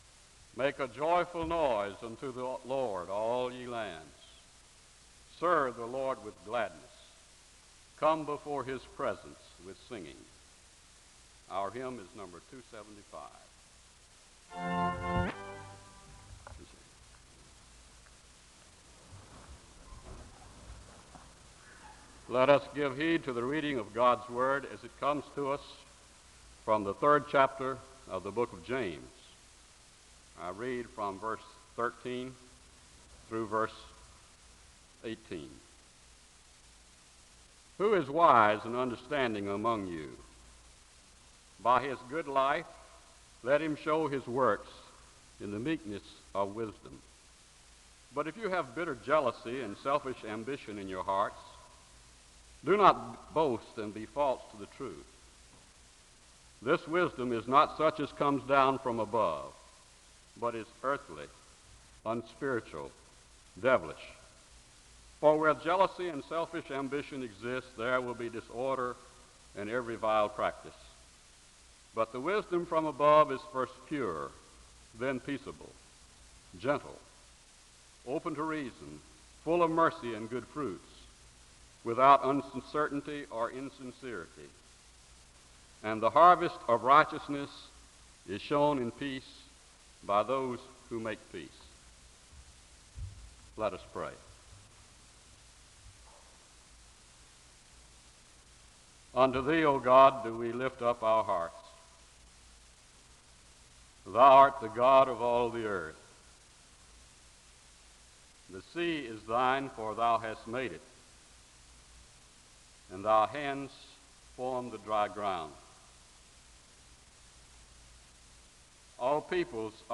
The service begins with a Scripture reading from James 3:13-18 and a word of prayer (00:00-05:34).
The choir sings the anthem (08:41-12:10).
The service ends with a word of prayer (38:08-38:50).
Location Wake Forest (N.C.)